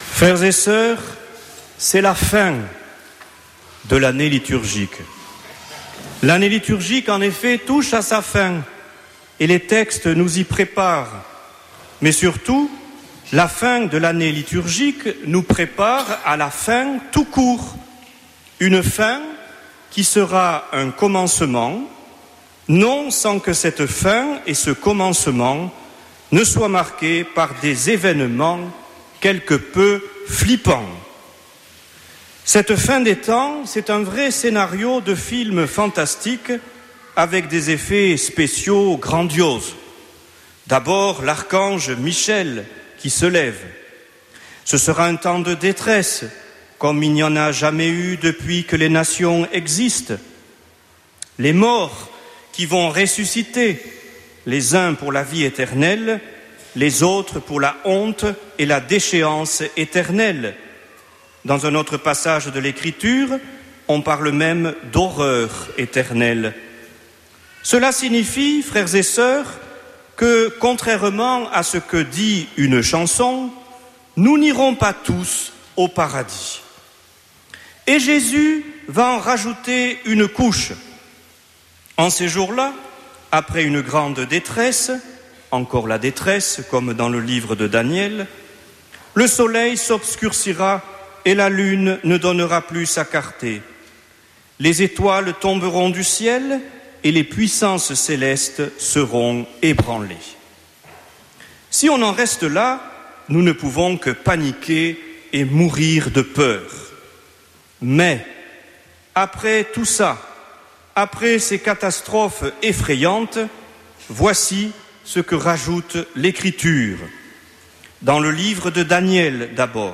dimanche 17 novembre 2024 Messe depuis le couvent des Dominicains de Toulouse Durée 01 h 30 min
Homélie en direct du Christ Roi à Toulouse le 17 novembre